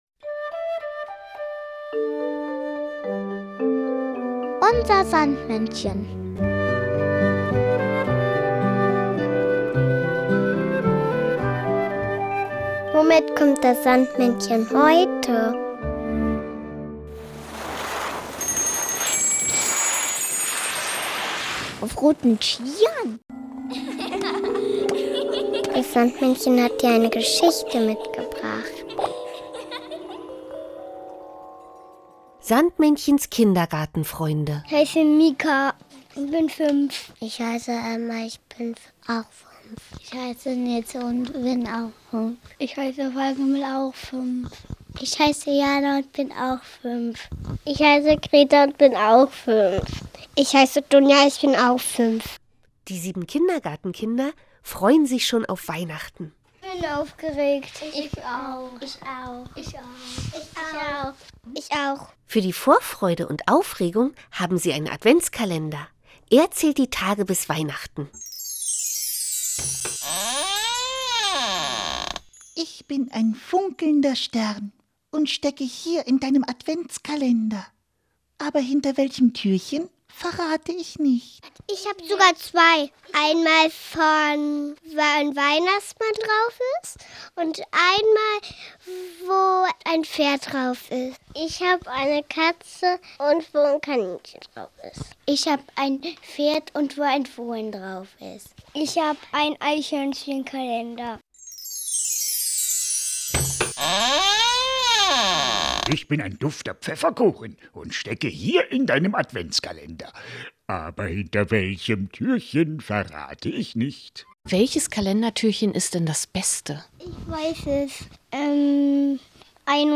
Das Sandmännchen hat dir aber nicht nur diese Geschichte mitgebracht, sondern auch das Weihnachtslied "Der Adventskalender".